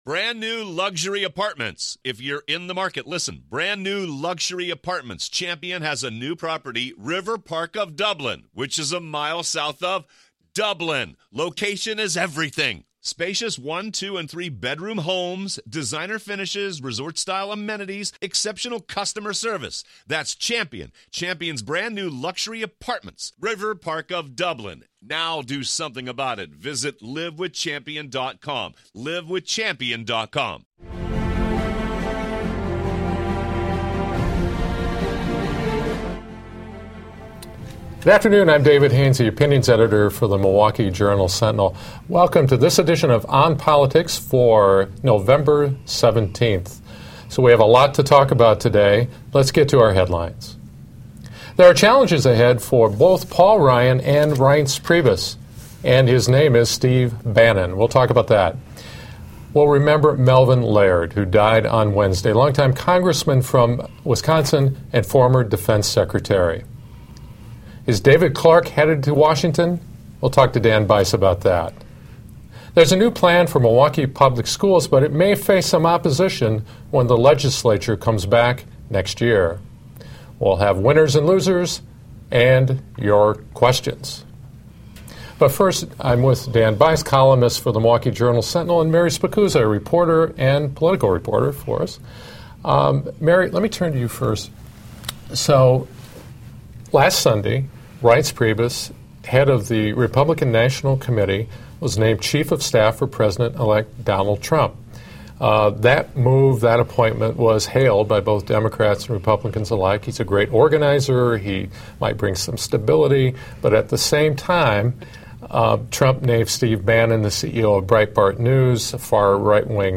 Our panel discusses the challenges ahead for two Wisconsin conservatives, MPS Superintendent Darienne Driver's big new plan to reshape the struggling district, and remembers the incomparable Melvin Laird. Also, is Milwaukee's get-tough sheriff going to D.C.?